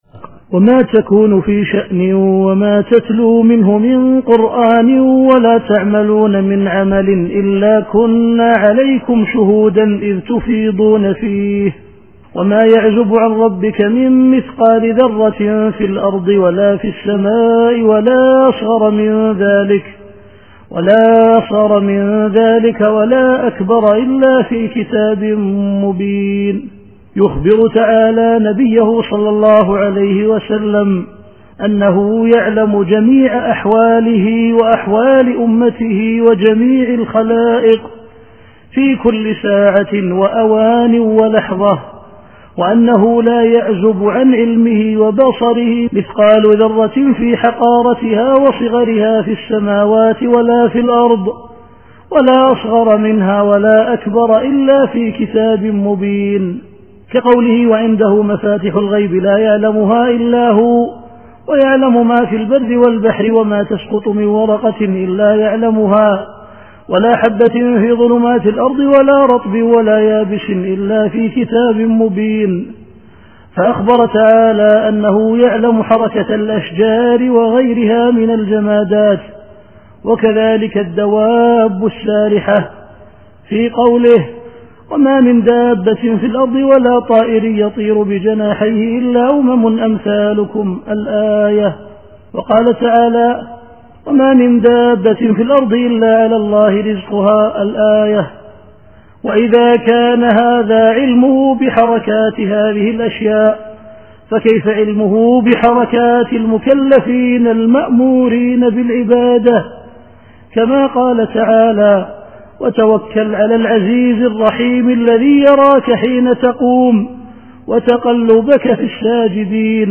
التفسير الصوتي [يونس / 61]